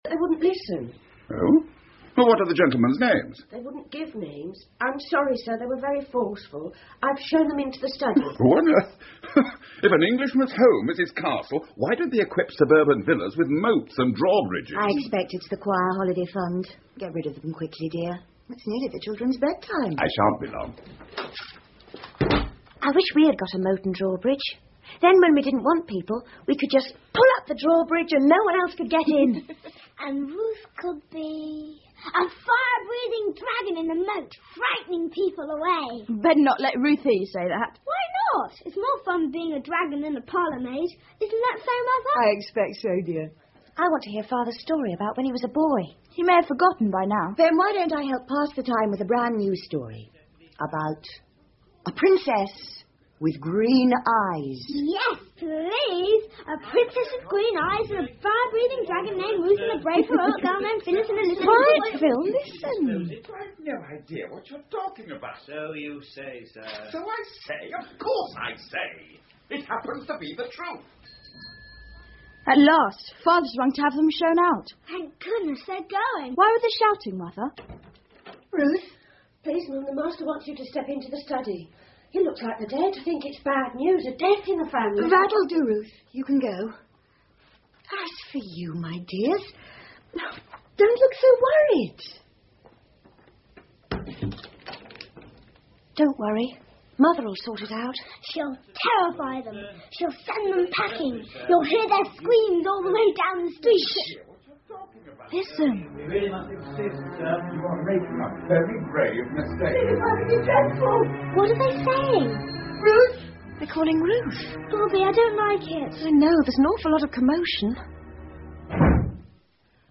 铁道少年 The Railway Children 儿童广播剧 2 听力文件下载—在线英语听力室